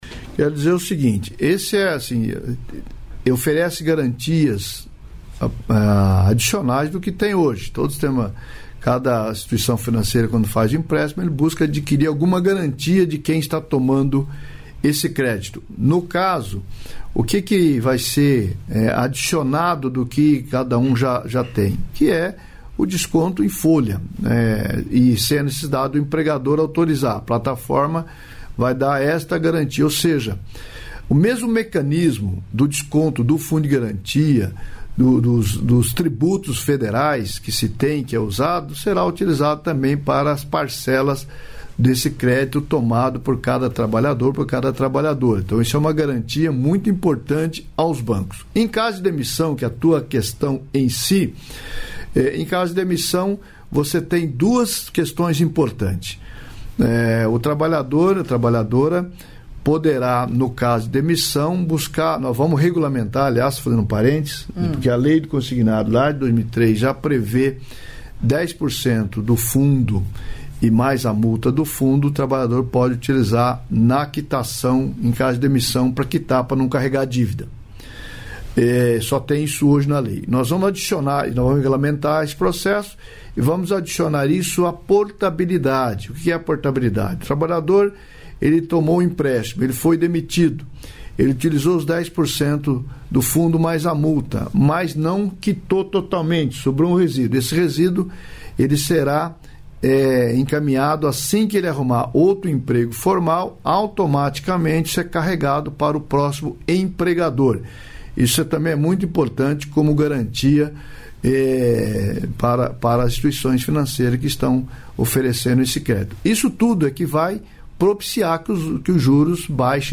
Trecho da participação do ministro do Trabalho e Emprego, Luiz Marinho, no programa "Bom Dia, Ministro" desta quinta-feira (13), nos estúdios da EBC, em Brasília.